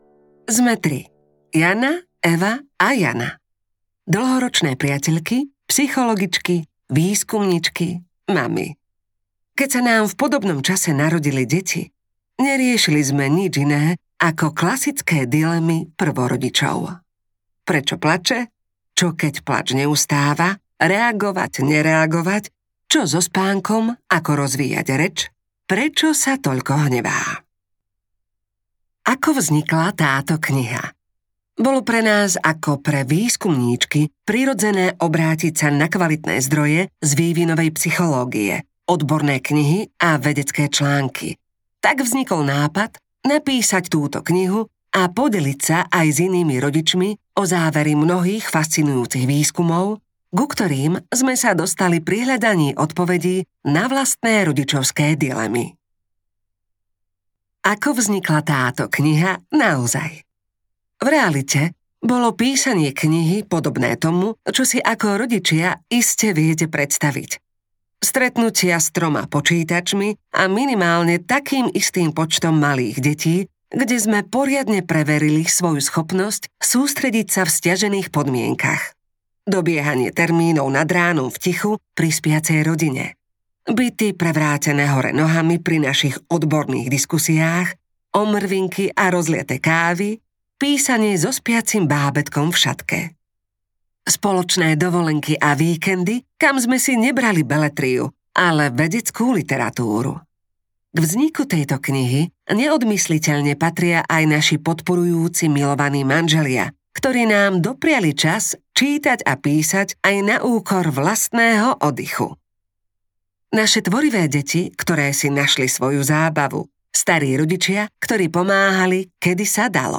Psychológia pre milujúcich rodičov audiokniha
Ukázka z knihy